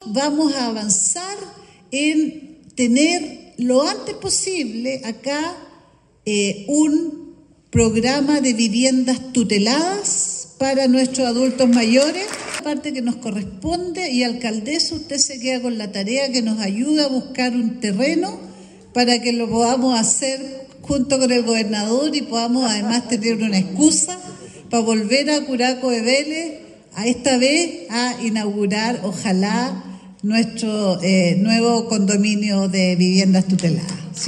La ministra de Desarrollo Social y Familia, Jeanette Vega, se refirió a este proyecto.